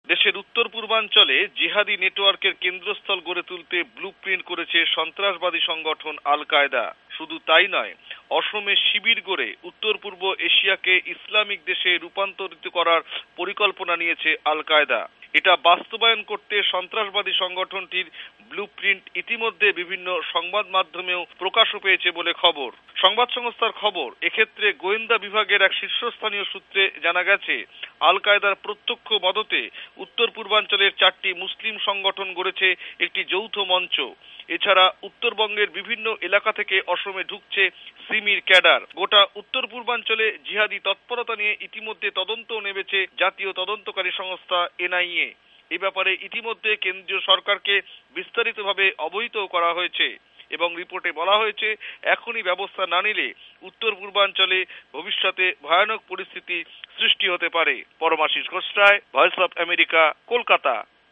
টেলিফোন বার্তা